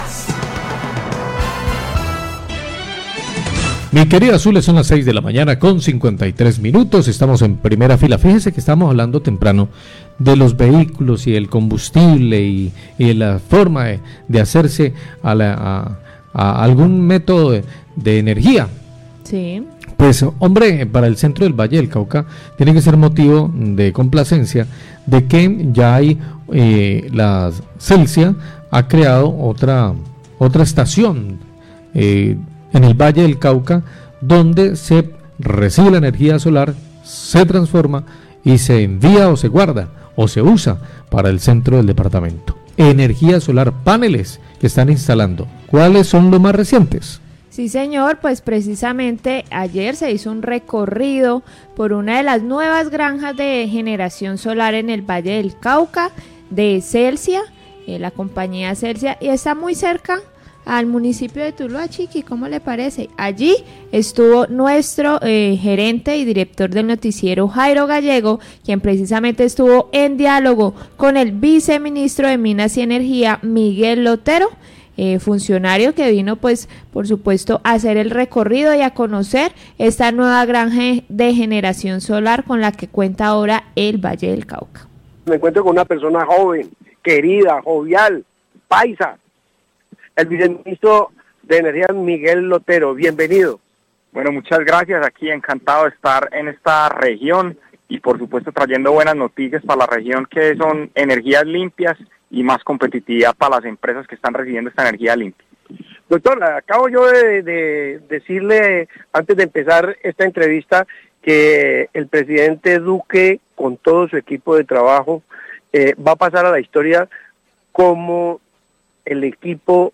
Entrevista con el viceministro de energía sobre la importancia de la generación de energías limpias, Los Robles 655am
Radio
Sobre la apertura de la granja y otros proyectos de energía renovable habló el viceministro de energía, Miguel Lotero Robledo.